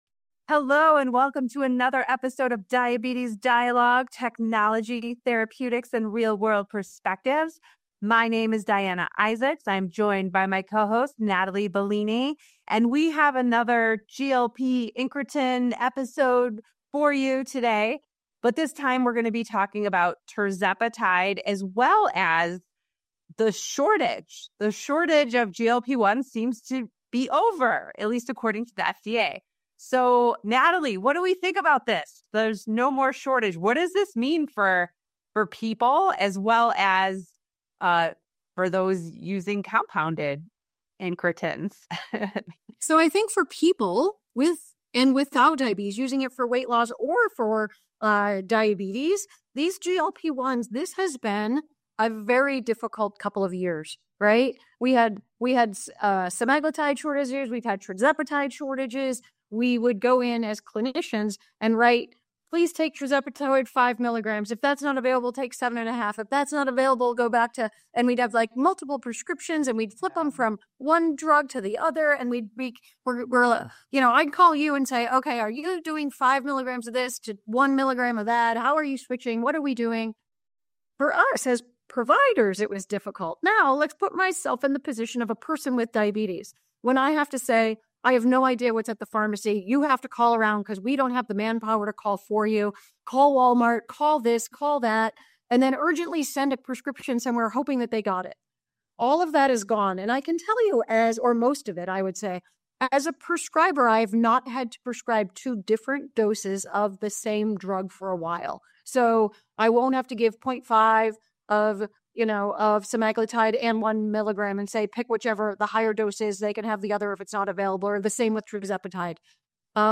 Turning specifically to tirzepatide, the hosts discuss findings from the SURMOUNT-1 trial, now with a 3-year follow-up, which demonstrated sustained weight loss for doses up to 20% in patients with obesity.